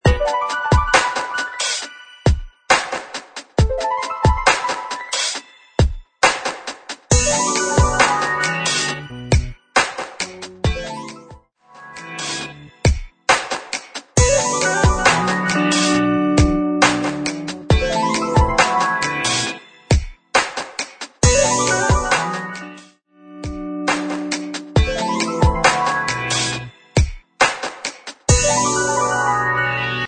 136 BPM
Smooth Urban